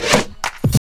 30DR.BREAK.wav